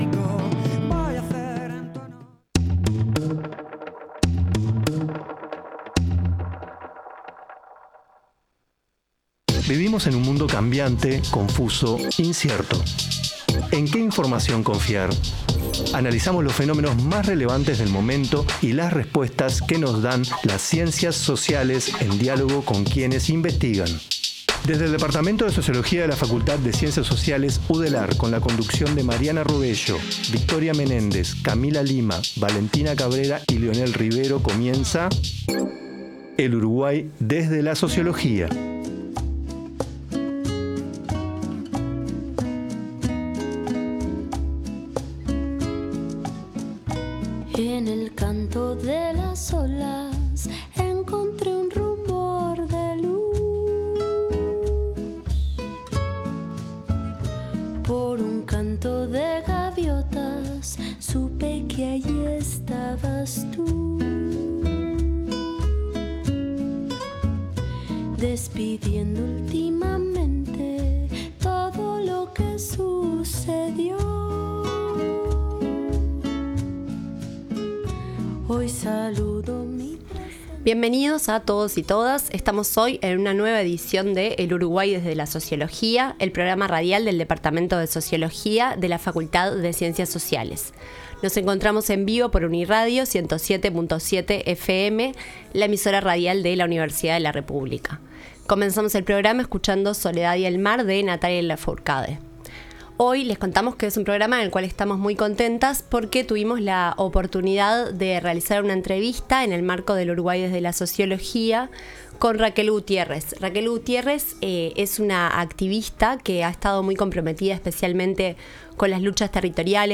La entrevista se realizó en el marco de su visita a la Facultad de Ciencias Sociales de la Universidad de la República (Udelar).